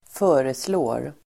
Uttal: [²f'ö:reslå:r]